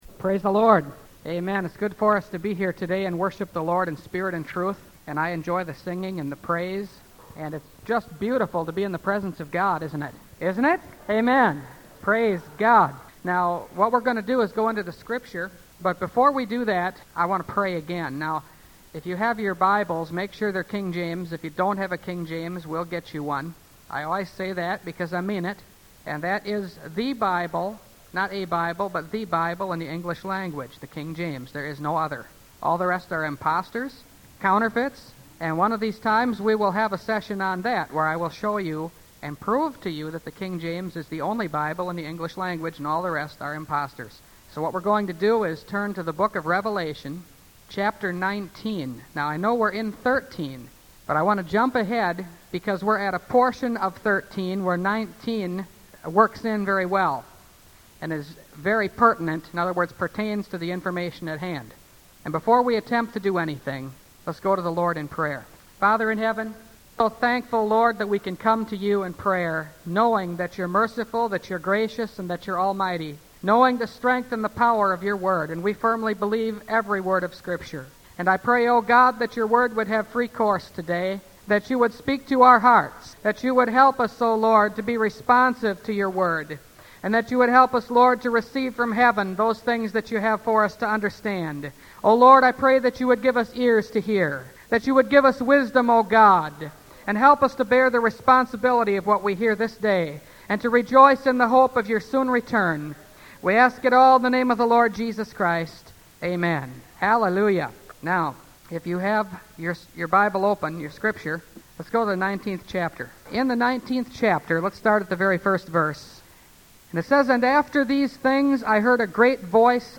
Revelation Series – Part 26 – Last Trumpet Ministries – Truth Tabernacle – Sermon Library